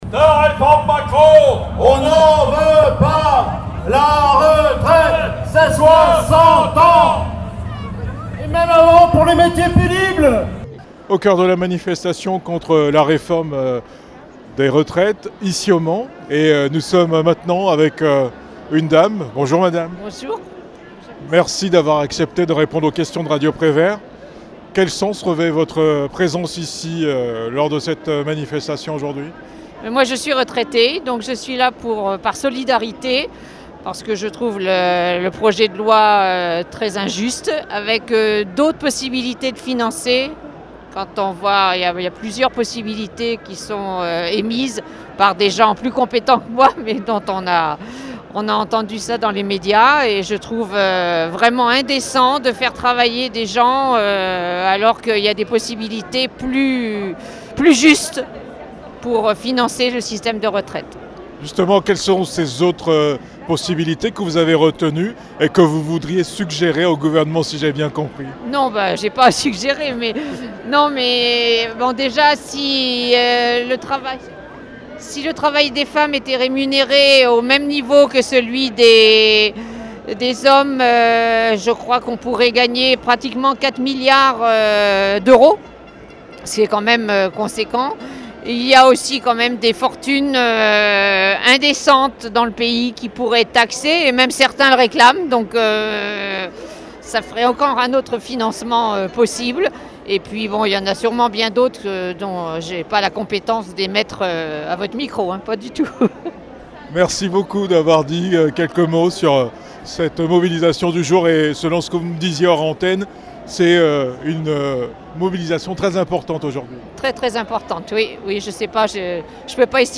Manifestation contre la réforme des retraites au Mans
Manifestation contre la réforme des retraites - Femme anonyme